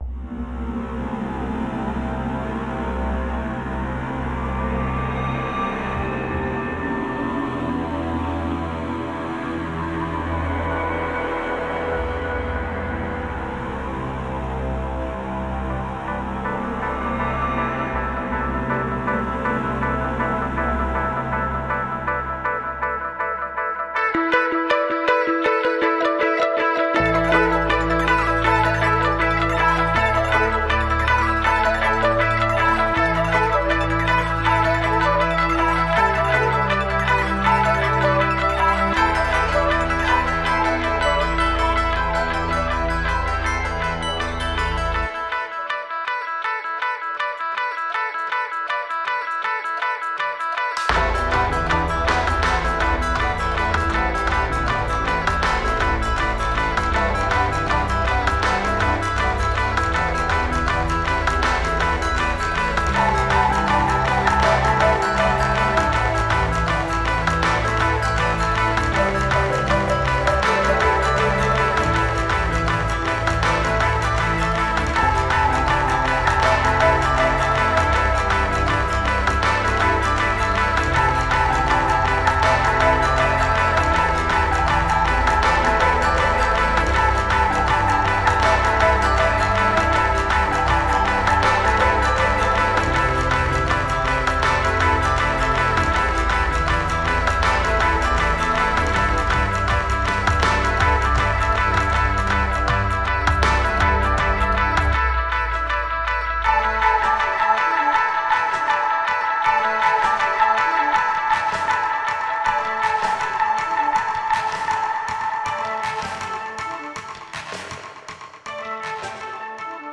Ambient Trance Prog für Kopf und Körper aus Offenbach aM